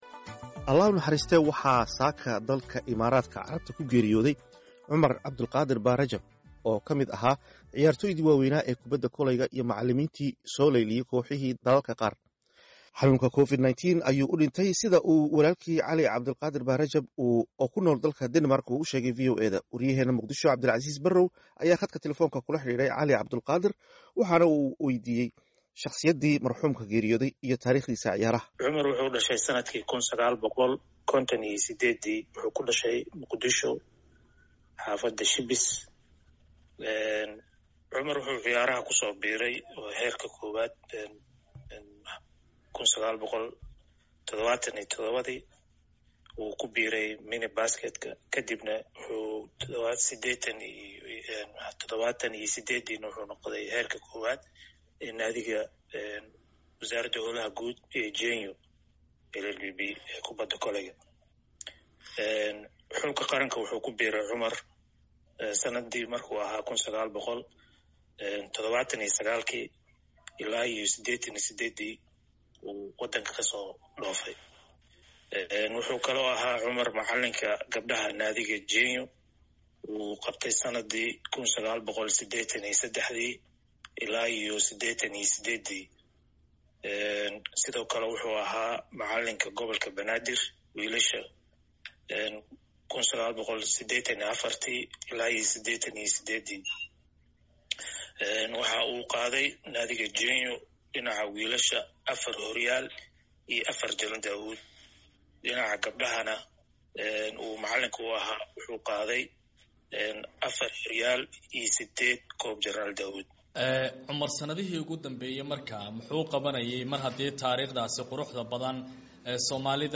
Khadka taleefanka kula xiriiray